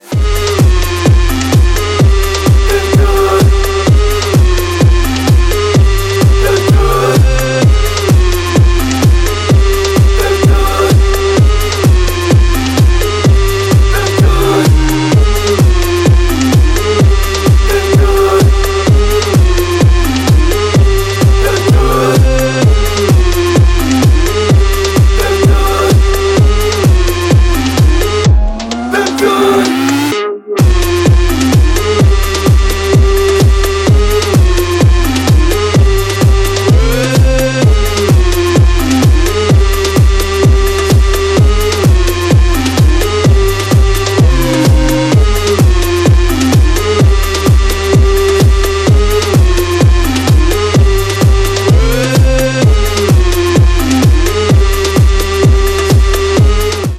• Качество: 192, Stereo
громкие
Хип-хоп
русский рэп
электронная музыка
качающие